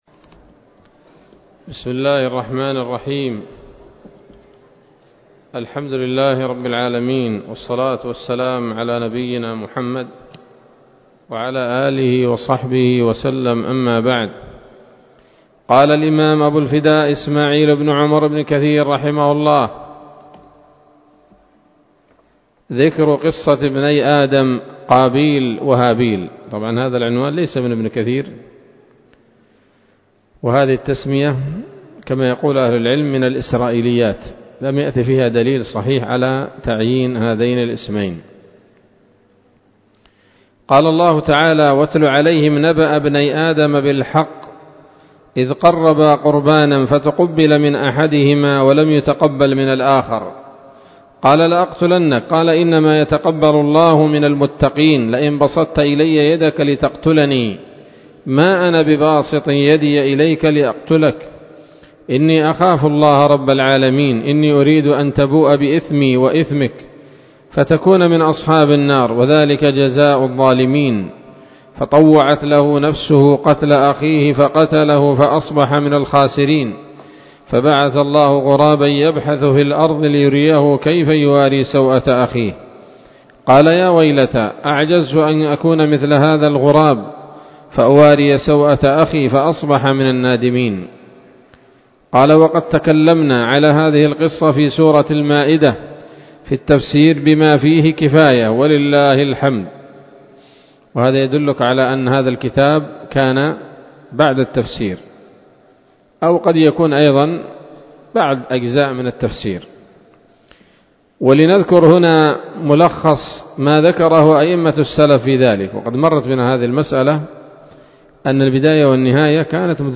الدرس الخامس عشر من قصص الأنبياء لابن كثير رحمه الله تعالى